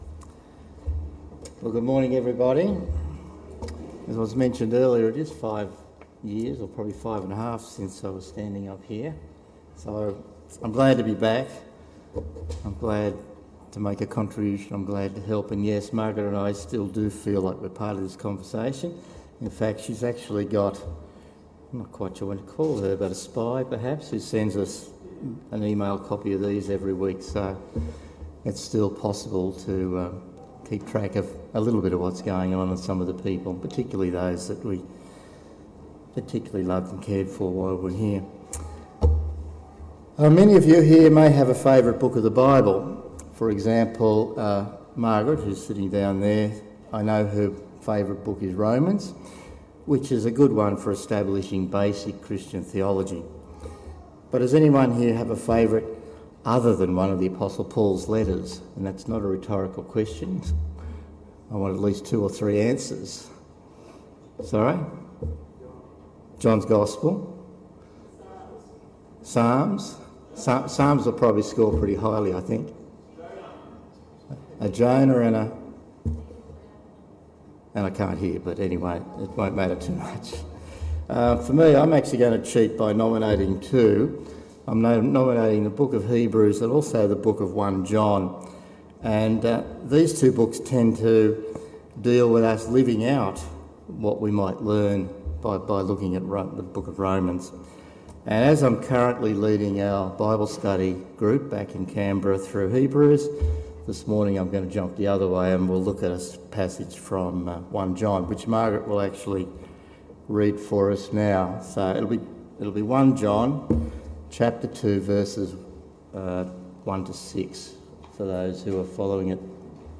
31 Jan 2021 – Parkes Baptist Church